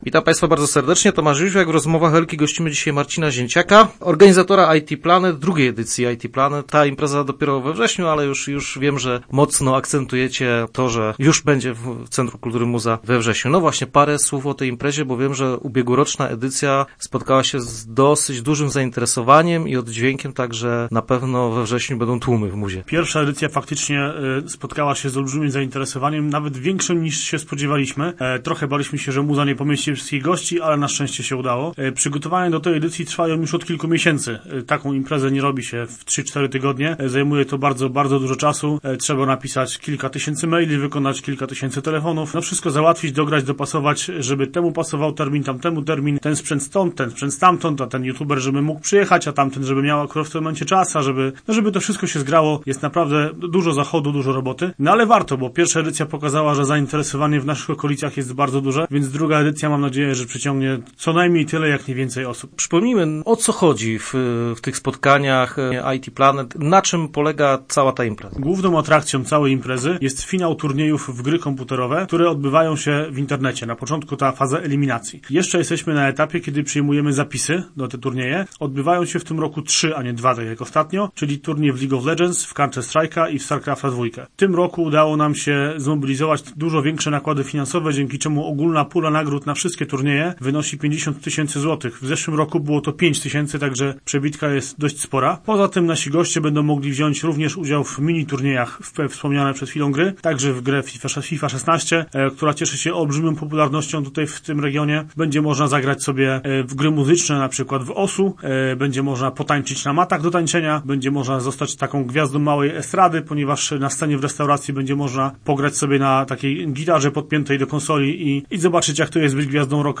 Start arrow Rozmowy Elki arrow Komputerowe show